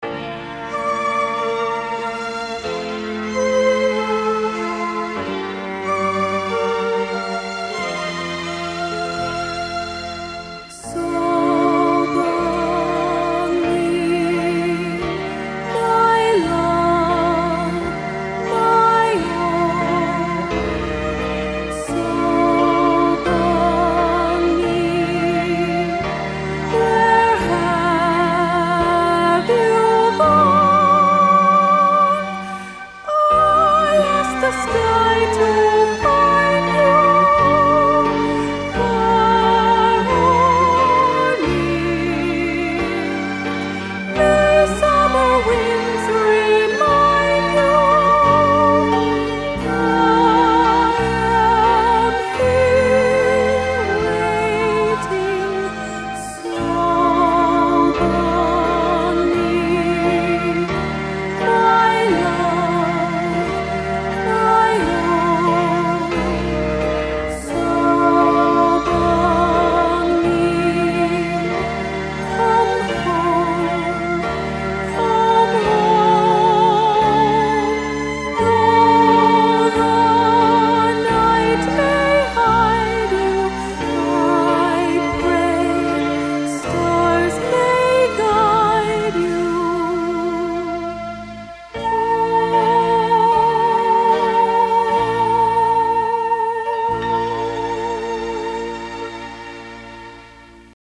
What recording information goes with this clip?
Seoul Olympics Arts Festival '88